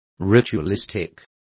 Complete with pronunciation of the translation of ritualistic.